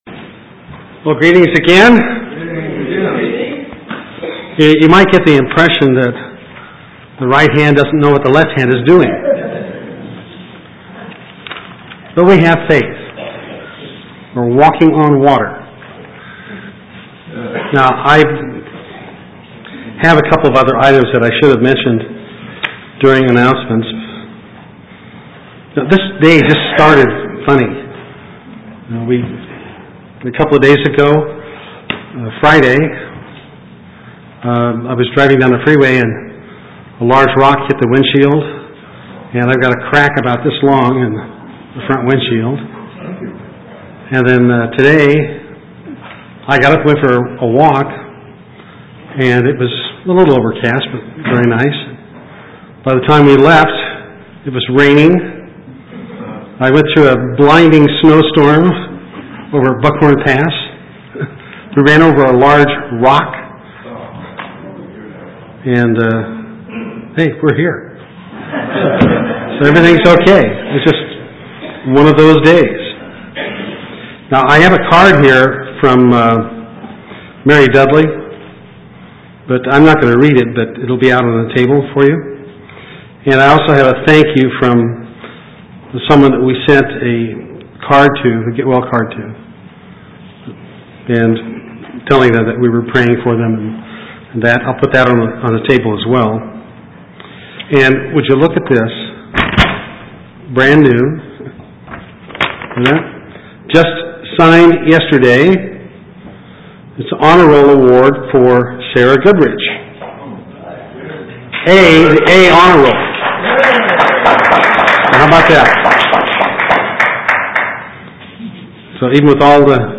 Given in Eureka, CA
Print Our Passover Examination Process UCG Sermon Studying the bible?